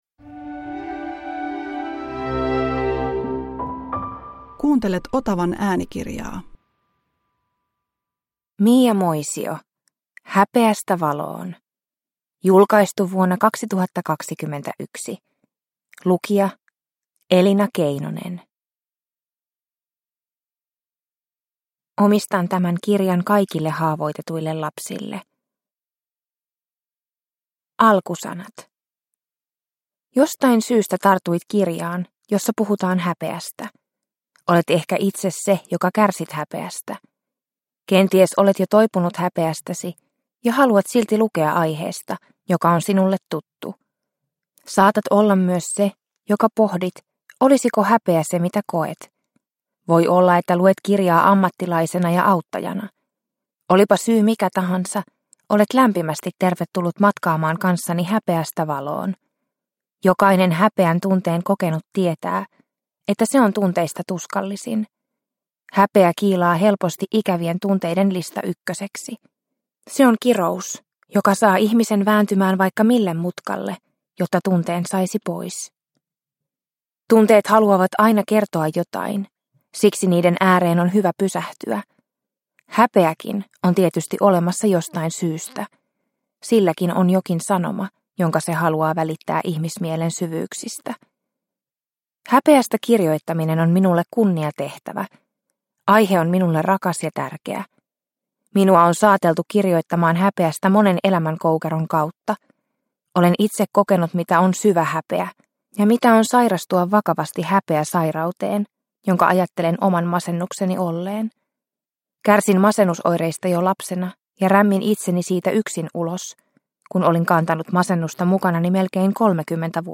Häpeästä valoon – Ljudbok – Laddas ner